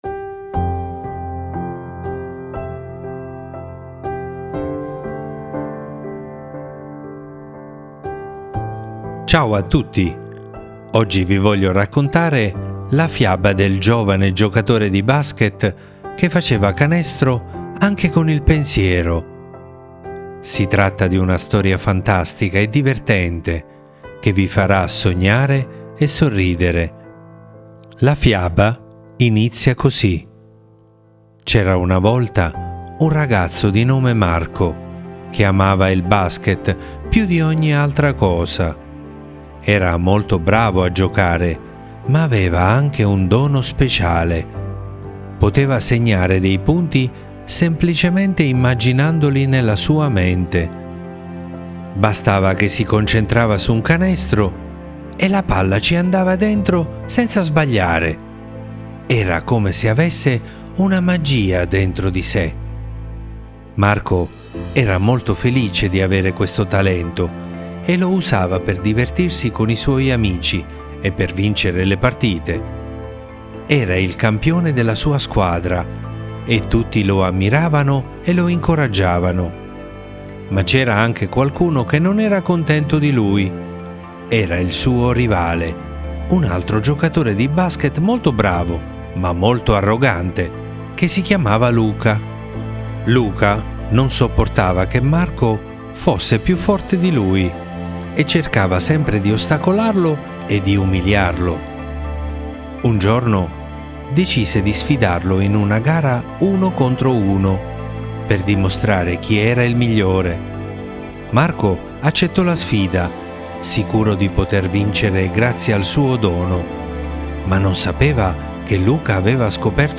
mamma legge la fiaba